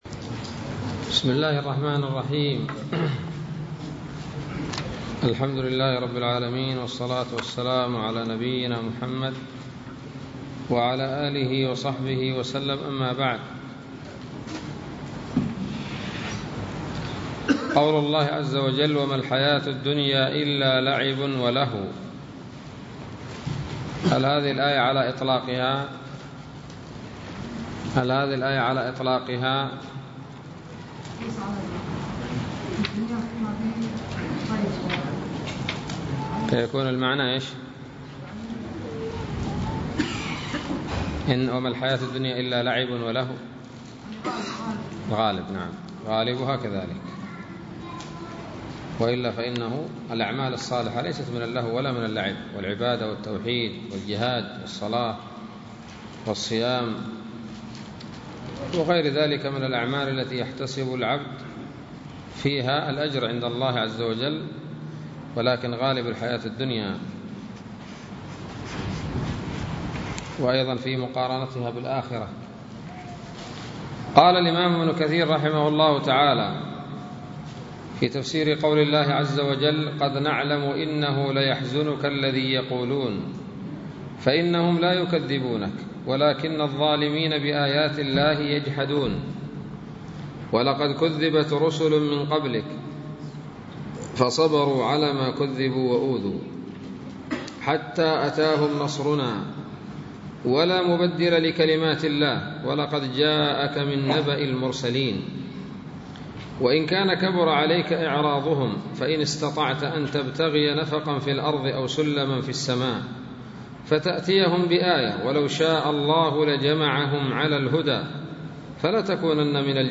الدرس السابع من سورة الأنعام من تفسير ابن كثير رحمه الله تعالى